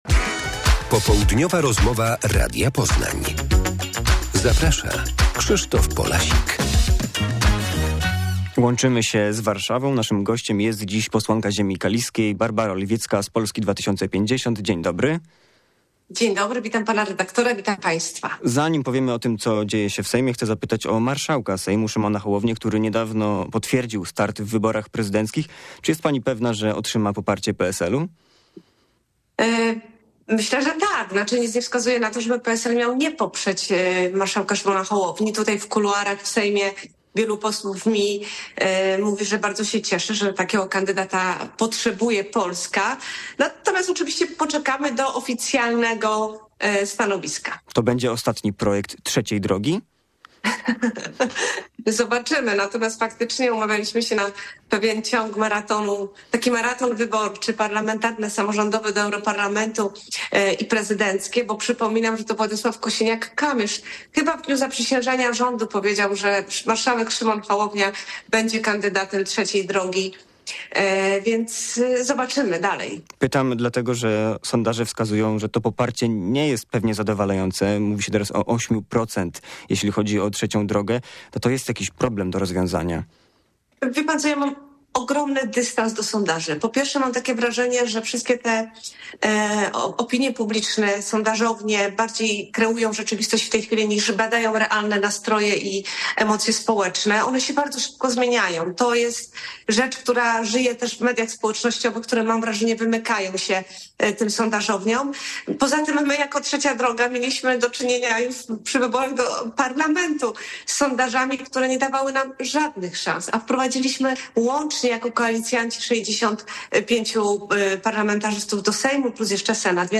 Popołudniowa rozmowa Radia Poznań - Barbara Oliwiecka
W Sejmie trwają prace między innymi nad zamrożeniem cen energii do września 2025 roku i obniżeniem składki zdrowotnej dla przedsiębiorców. O pracach mówi posłanka Barbara Oliwiecka z Polski 2050